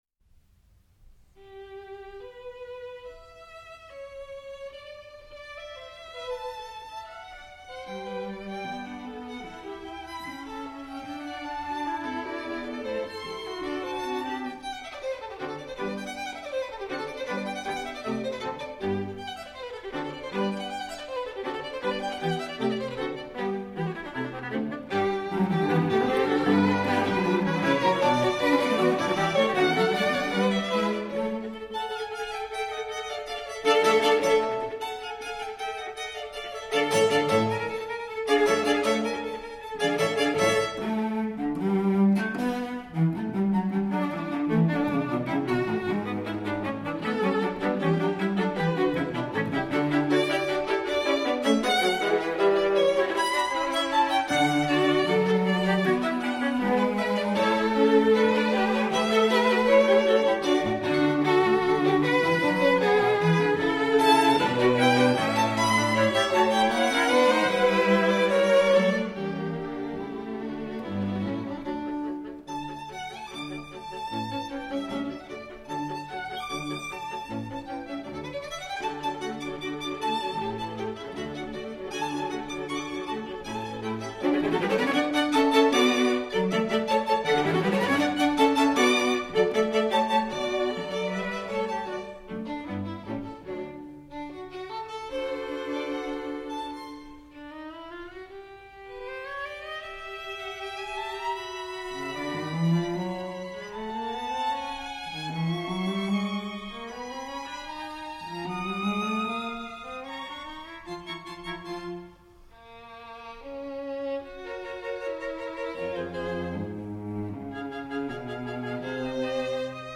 String Quartet in G major
Molto allegro